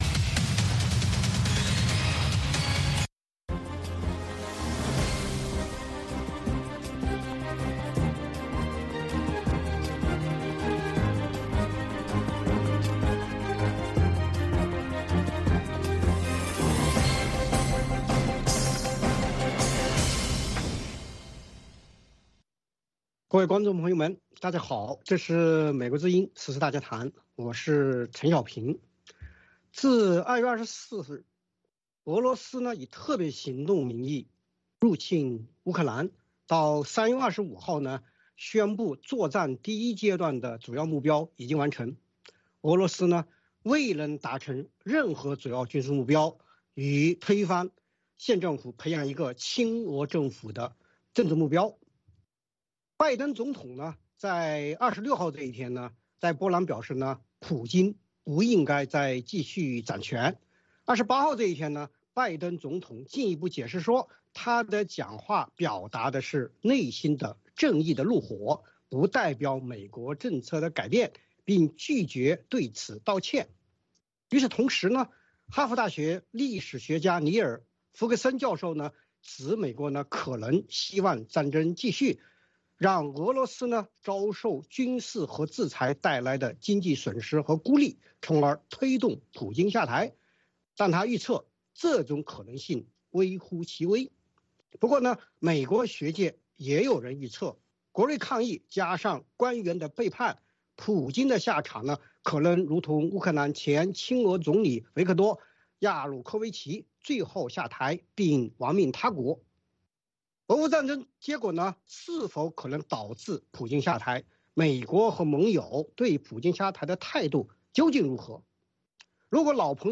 美国之音中文广播于北京时间晚上9点播出《VOA卫视》节目(电视、广播同步播出)。